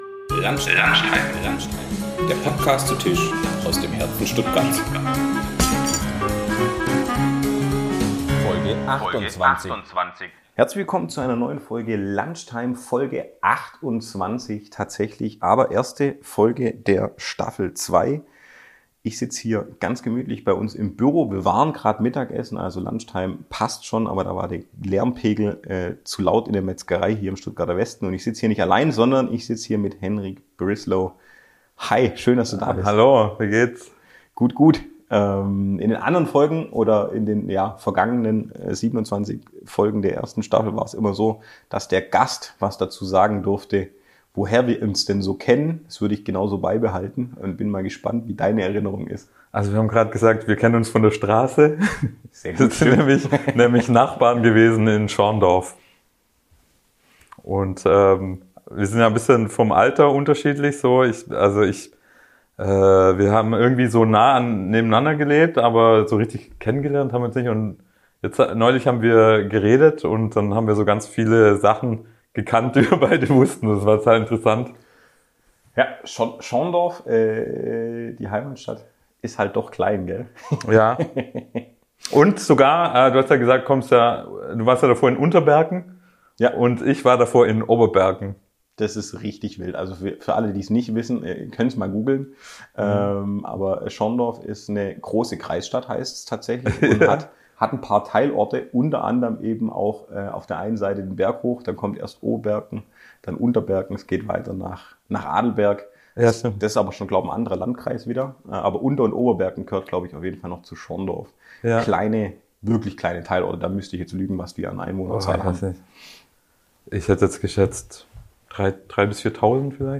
In dieser Folge nutzen wir mein Büro nach dem Lunch in der Metzgerei, wo aufgrund der Mittagstisch-Frequenz eine Aufnahme nicht mehr möglich war für unser Gespräch. So sind wir ungestört und können uns durch die unbewusst gemeinsame Zeit seit 1996 treiben lassen...